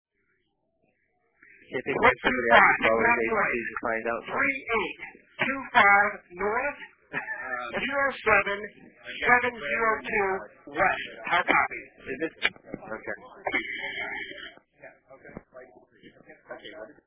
The controller transposed two digits in the initial transmission to the flight lead, Quit 25.  The coordinates initially provided  at 0946 EDT were 3825N 07702W. Here is the audio clip from NEADS tape DRM 2, Channel 13, cut 131828.